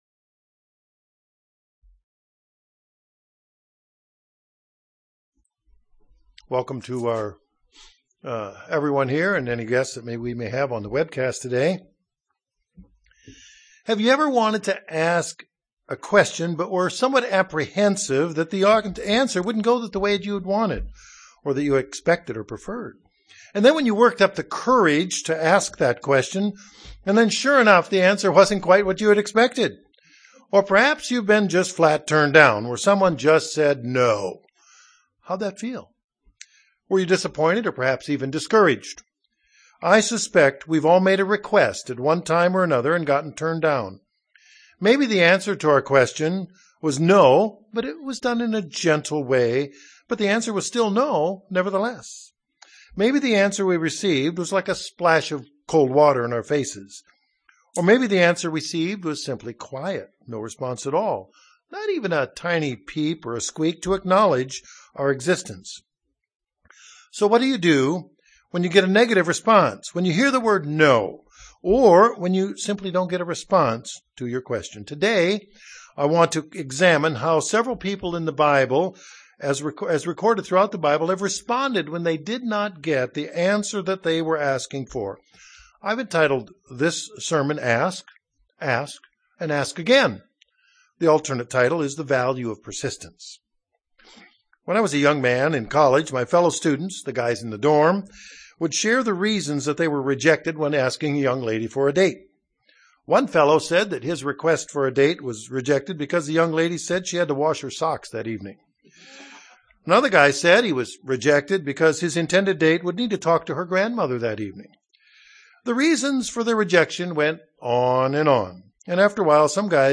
UCG Sermon Notes Have you ever wanted to ask a question, but were somewhat apprehensive that the answer wouldn't go the way that you wanted, or expected or preferred?